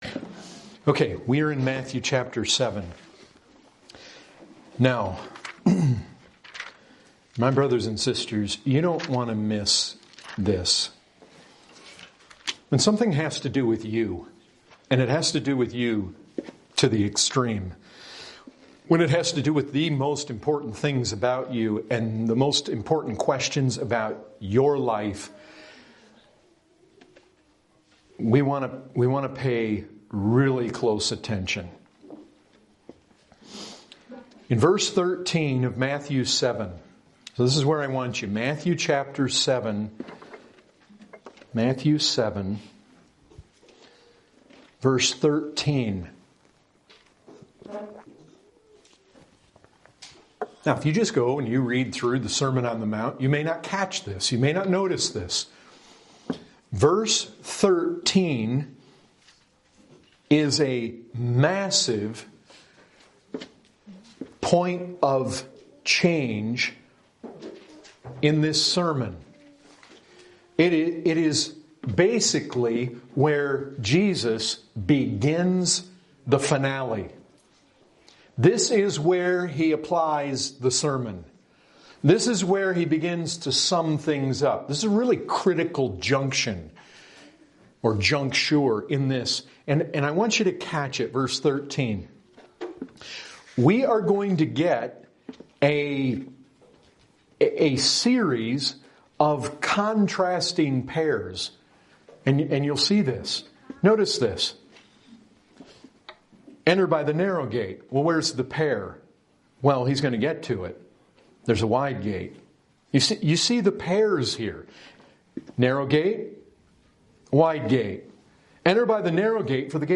Category: Full Sermons